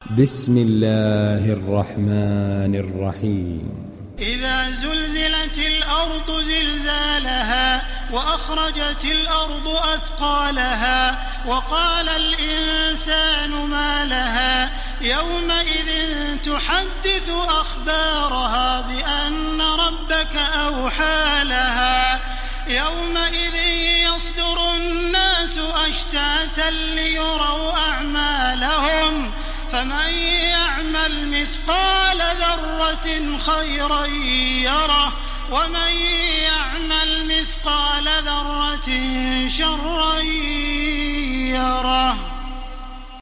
İndir Zelzele Suresi Taraweeh Makkah 1430